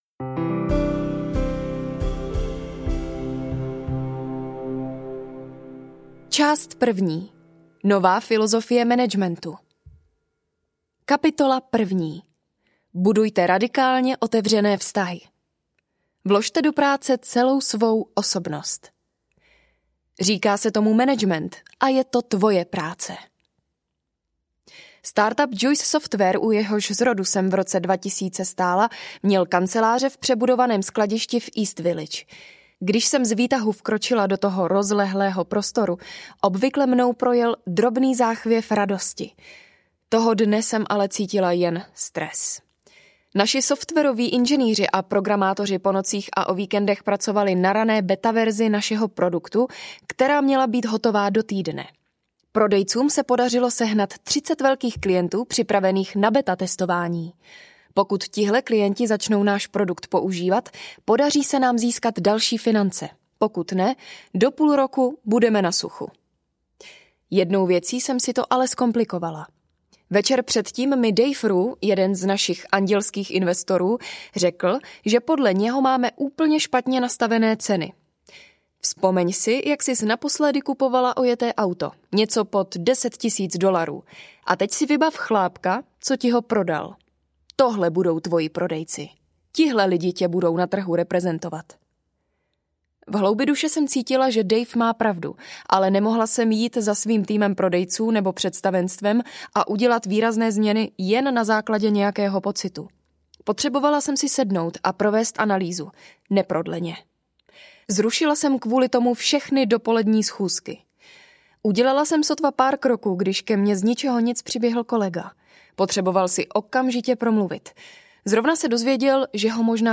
Radikální otevřenost audiokniha
Ukázka z knihy